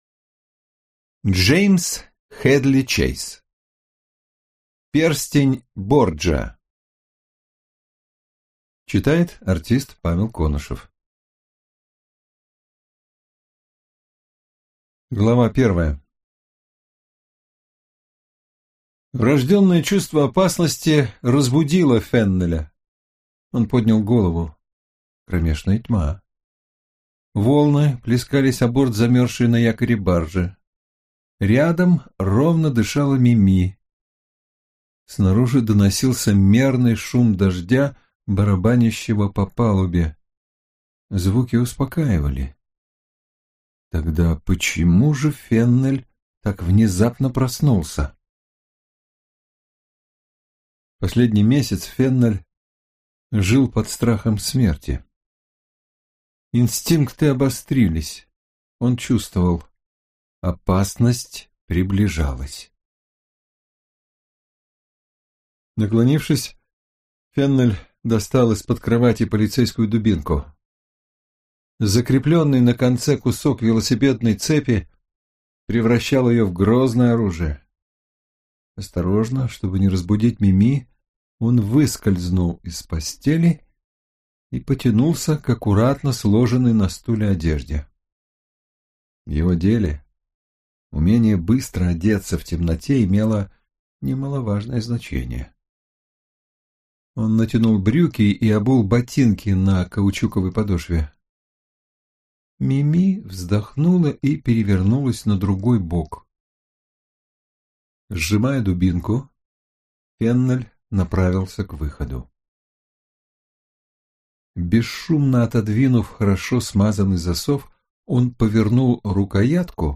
Аудиокнига Перстень Борджиа | Библиотека аудиокниг